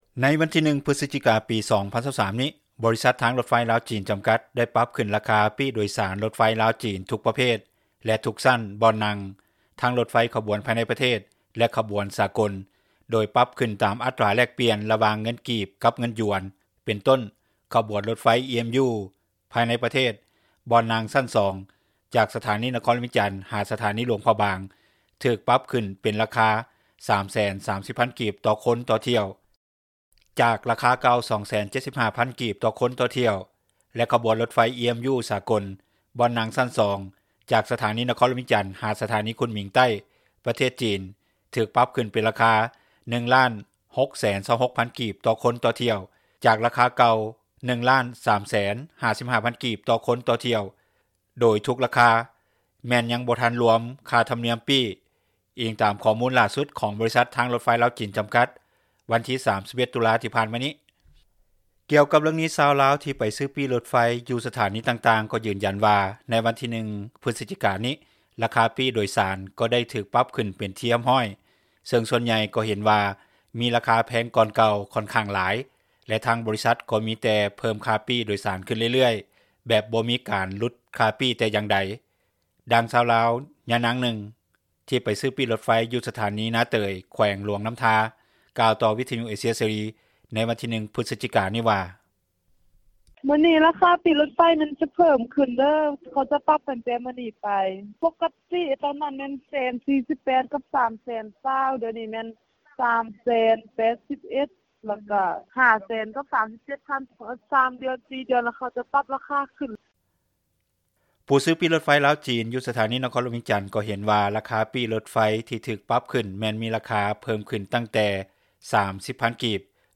ດັ່ງຊາວລາວນາງນຶ່ງ ທີ່ໄປຊື້ປີ້ຣົຖໄຟຢູ່ສະຖານີນາເຕີຍ ແຂວງຫຼວງນໍ້າທາ ກ່າວຕໍ່ວິທຍຸເອເຊັຽ ເສຣີ ໃນວັນທີ 01 ພຶສຈິການີ້ວ່າ: